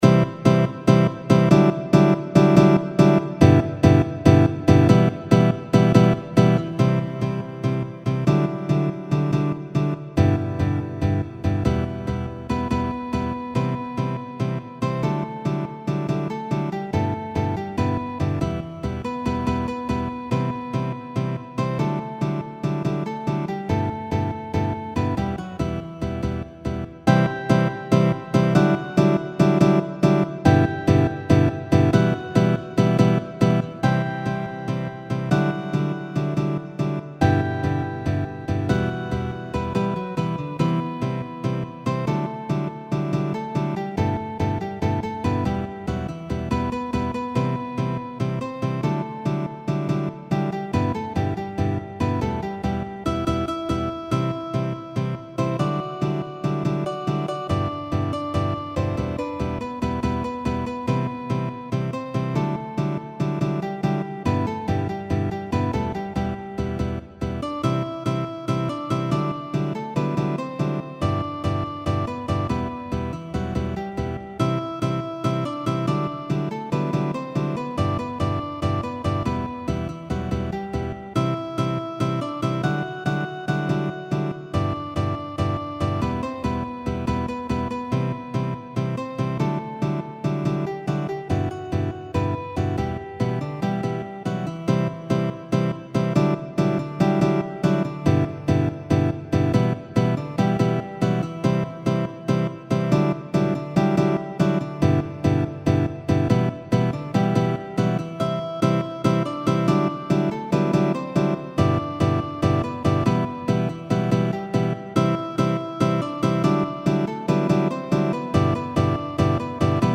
Guitar Quartet – Beginner/Intermediate Level
MIDI Mockup Recording